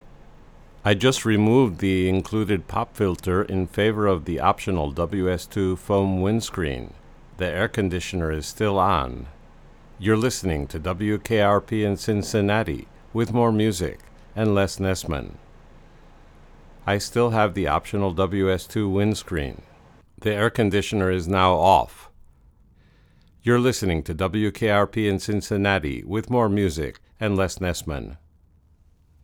Test recording 2 (flat) with optional WS2 windscreen instead
Especially if you listen with headphones, you will indeed hear some of the room and reverb.